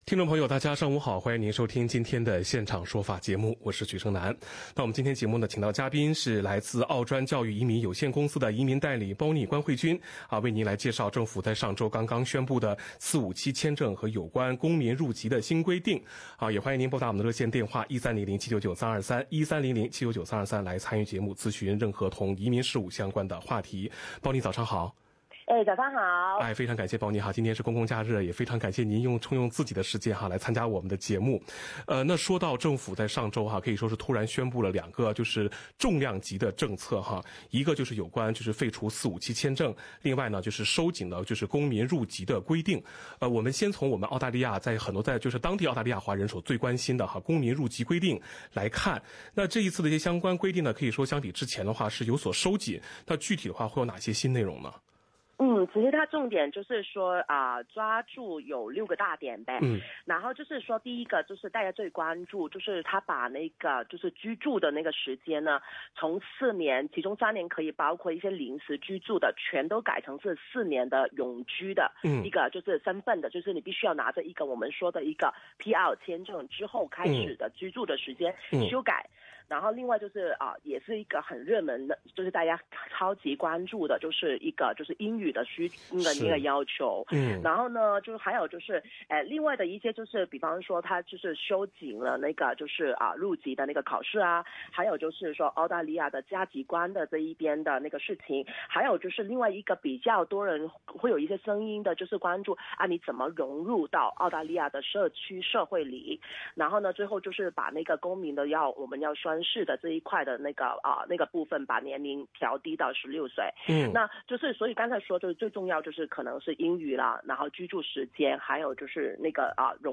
《现场说法》听众热线节目逢每周二上午8点30分至9点播出。